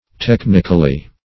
Technically \Tech"nic*al*ly\, adv.